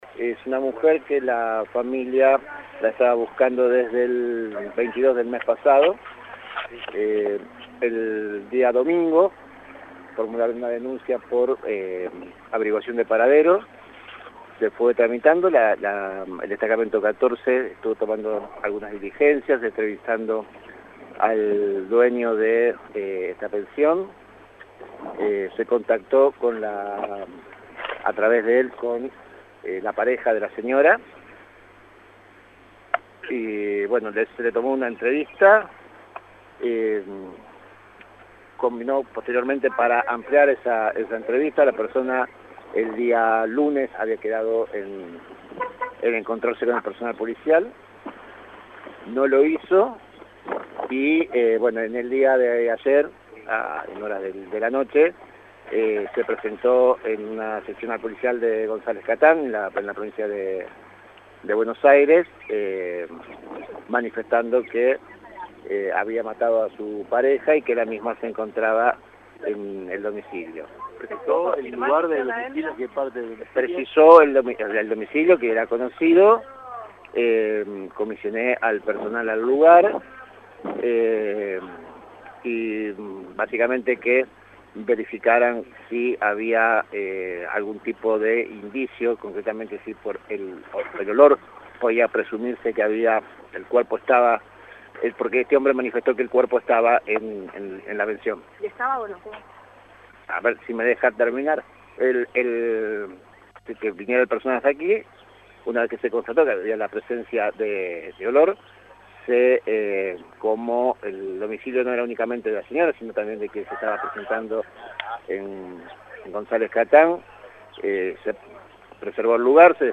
A raíz de la confesión de un hombre, la Agencia de Investigación Criminal realizó un operativo en la zona de Lisboa al 2900, en donde encontró en una pensión el cuerpo de una mujer, quien estaba reportada como desaparecida desde el  22 de septiembre. El fiscal que investiga la causa, Ademar Bianchini brindó declaraciones al móvil de LT3 confirmando que el cuerpo había sido ocultado debajo de un armario.